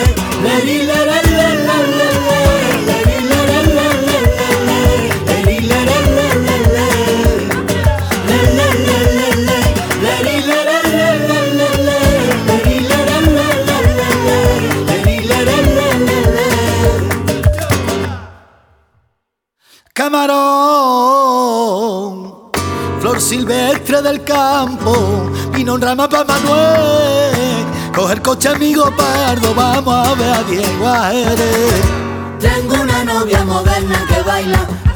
Flamenco